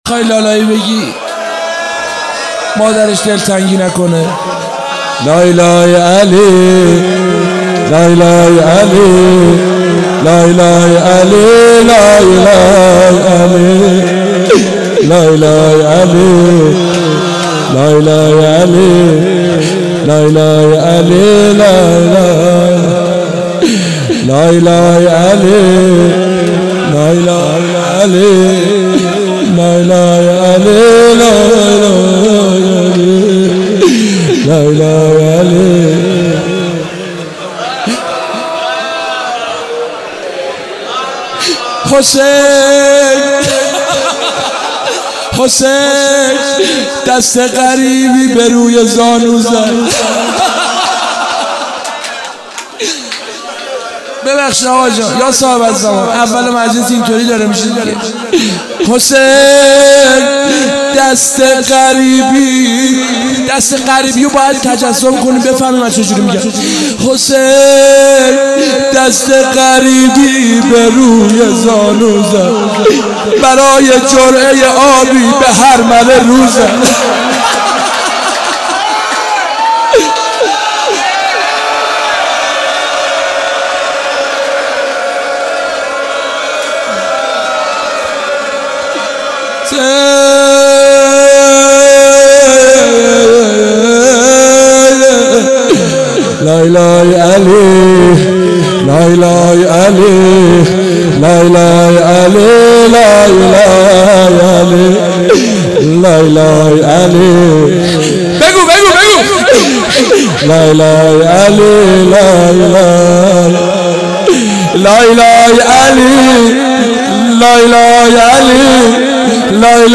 مناسبت : شب دوم محرم